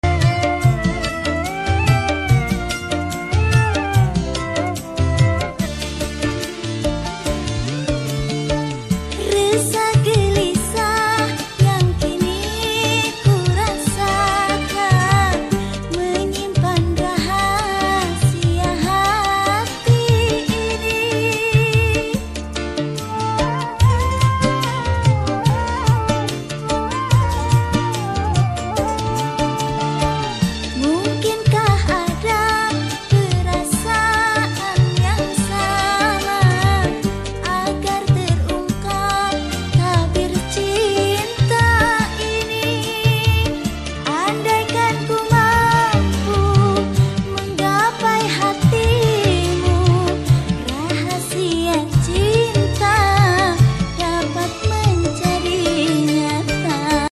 Lagu_Dangdut_Klasik sound effects free download